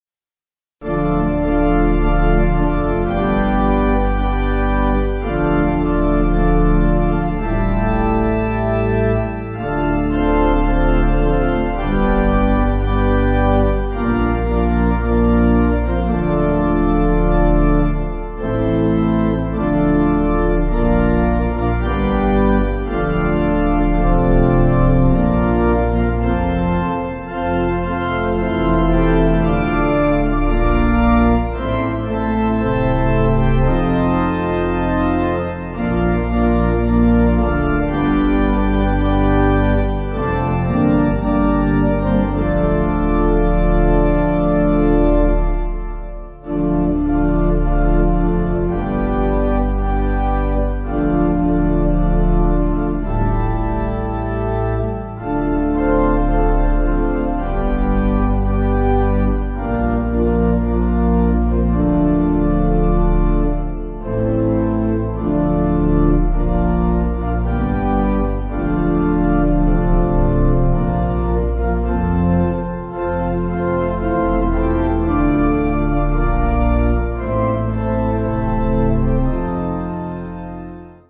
Organ
(CM)   3/Dm